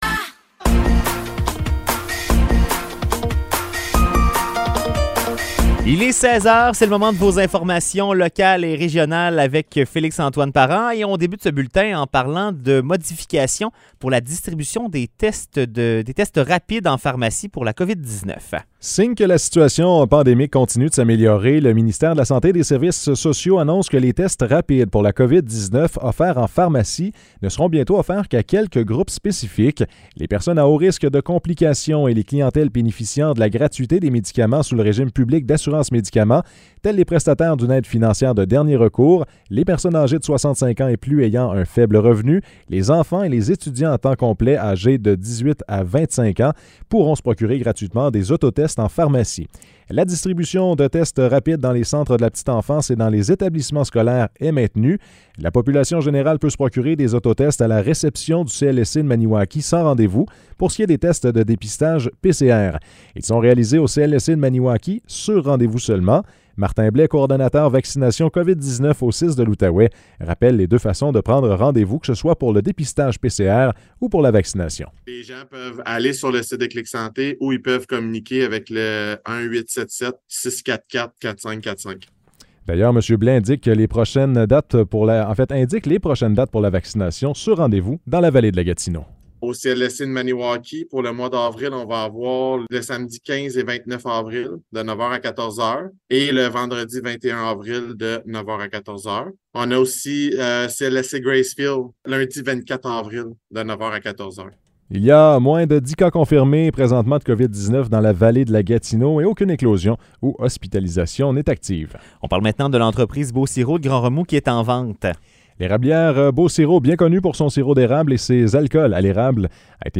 Nouvelles locales - 4 avril 2023 - 16 h